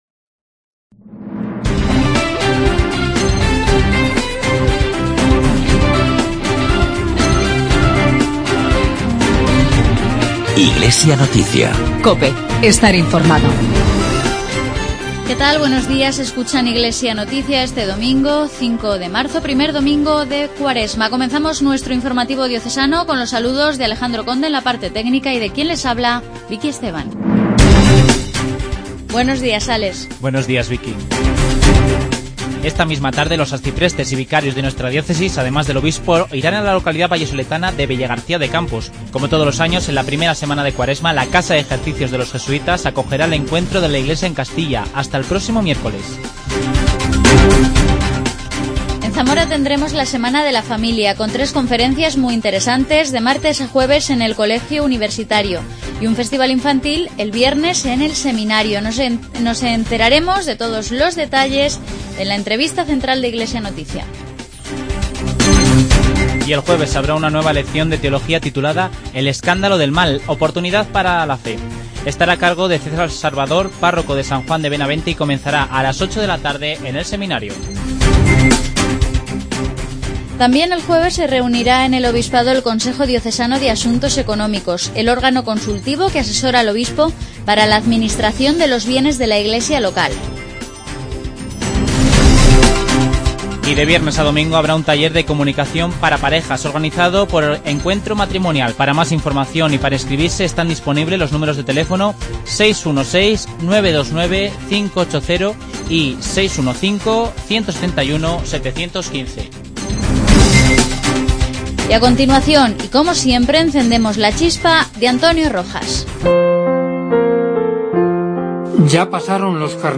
Informativo diocesano.